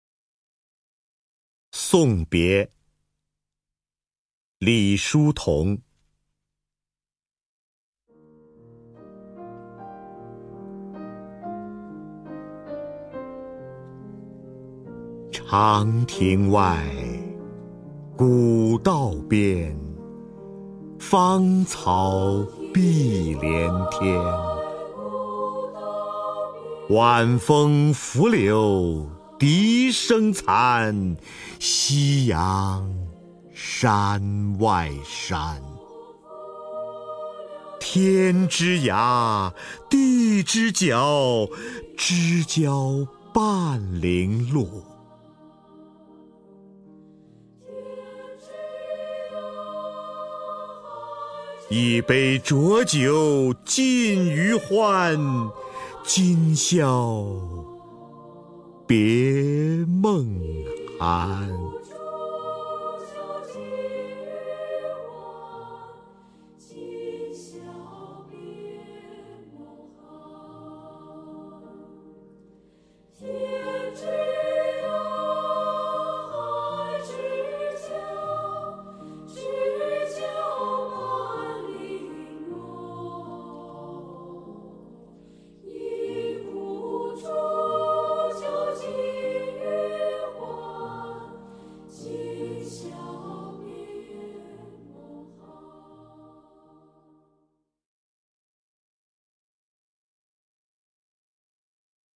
首页 视听 名家朗诵欣赏 瞿弦和
瞿弦和朗诵：《送别》(李叔同)